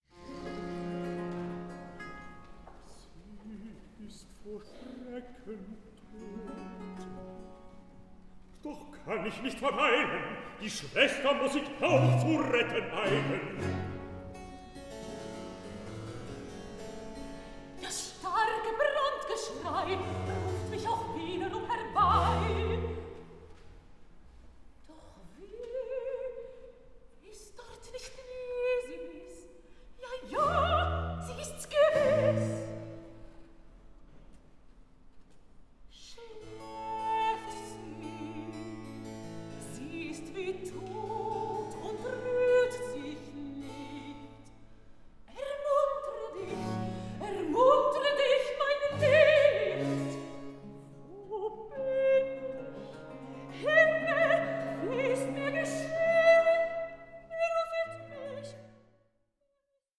ORIENTAL BAROQUE OPERA